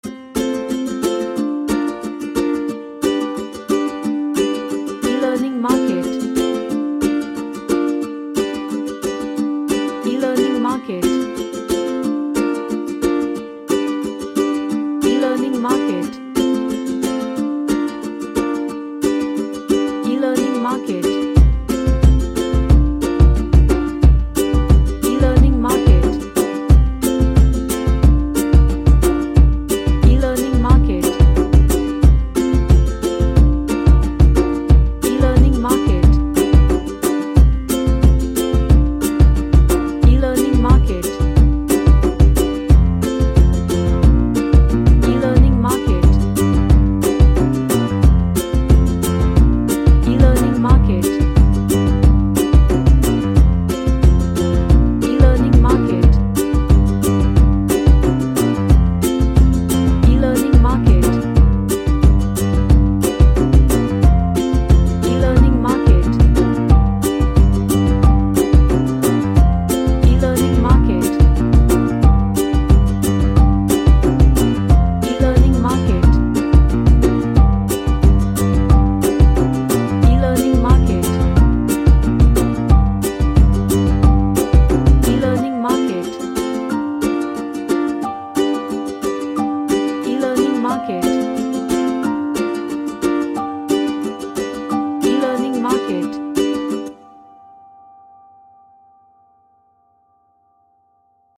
A happy sounding upbeat track
Happy / Cheerful